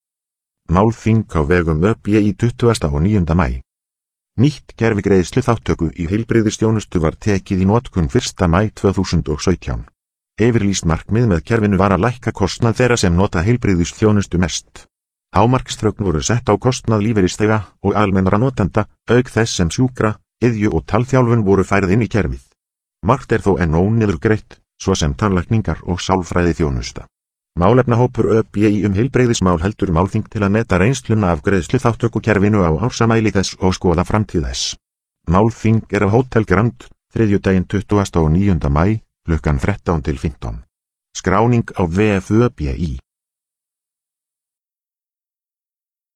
Hljóðritað hjá Hljóðbók slf. í Reykjavík, Stykkishólmi og í Quebec í Kanadaí maí 2018.